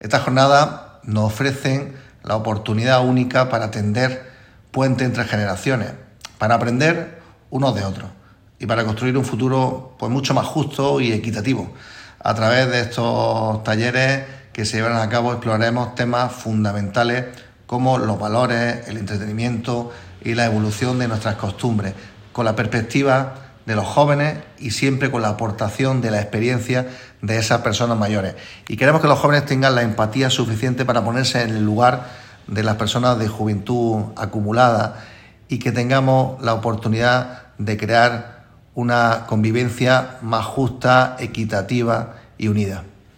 En esta jornada, celebrada en el Pabellón Municipal de Berja, el vicepresidente y diputado de Bienestar Social, Ángel Escobar, y el alcalde de Berja, José Carlos Lupión, han dado la bienvenida a las más de 150 personas que han asistido a las jornadas, entre ellos, alumnos de Educación Secundaria Obligatoria (ESO) y población mayor.
26-12_berja_diputado.mp3